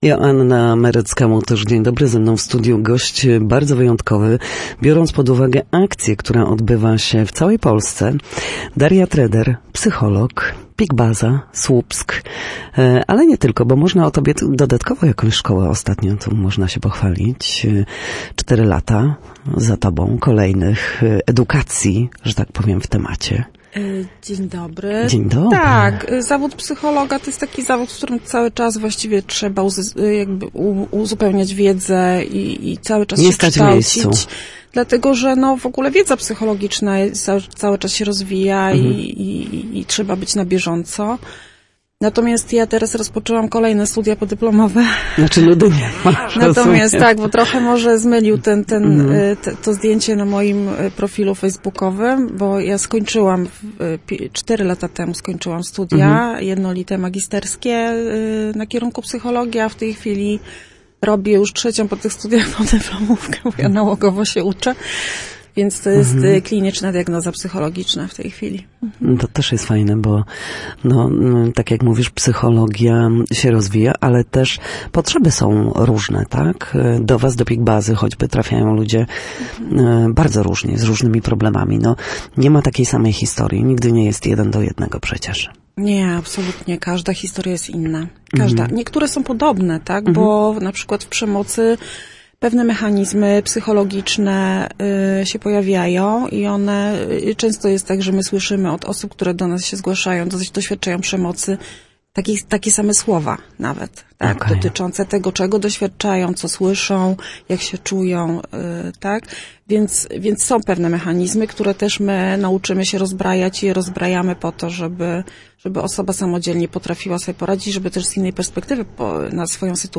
Na naszej antenie mówiła o ogólnopolskiej akcji „Dzieciństwo bez przemocy” oraz o tym, jak wspierać dziecko, aby stało się odpowiedzialnym i przyzwoitym człowiekiem, wolnym od traum i krzywd .